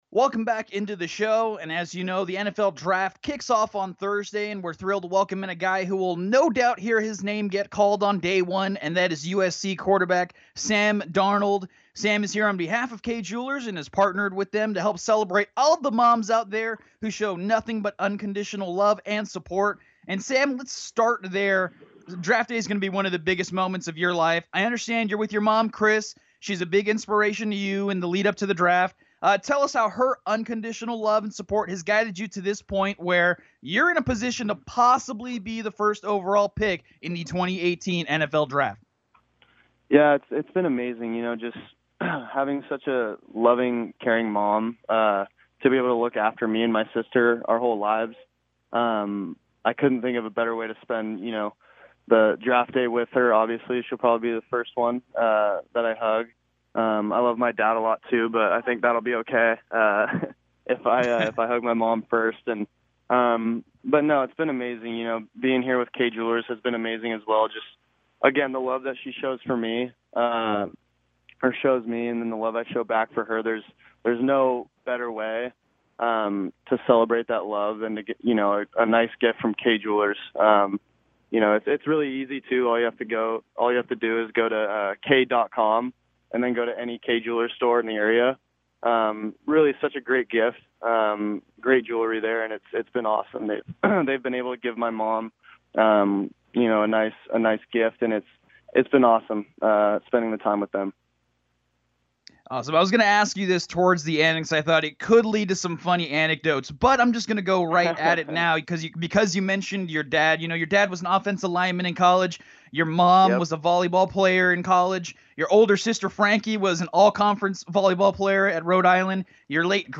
We crack open the Sports Byline USA Vault to share our interview with Super Bowl-bound quarterback Sam Darnold.